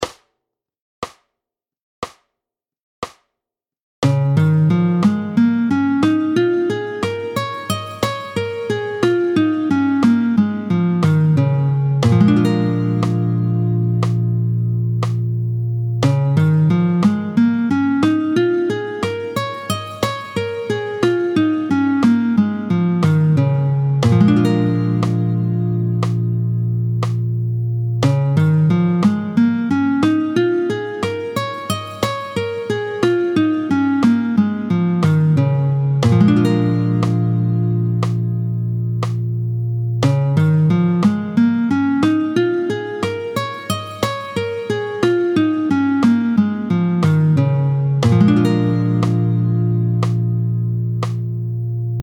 31-02 Doigté 2 en Sib, tempo 60